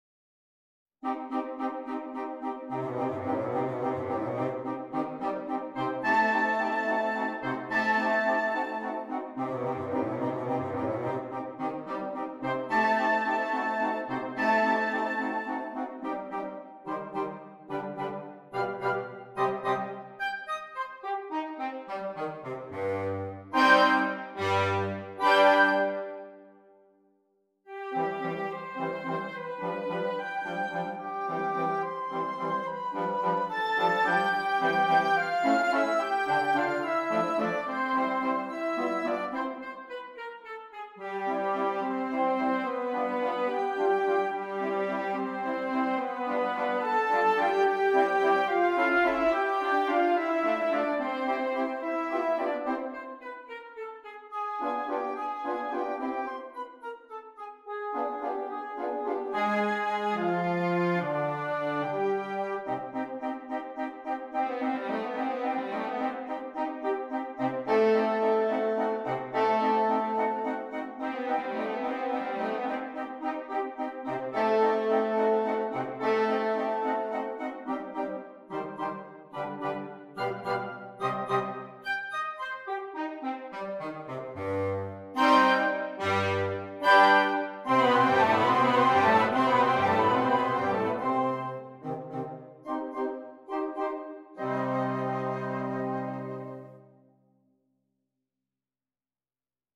• Interchangeable Woodwind Ensemble